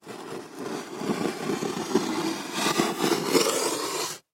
На этой странице собраны разнообразные звуки мачете: от резких ударов до плавного разрезания.
Вырезаем узоры на дереве острым концом мачете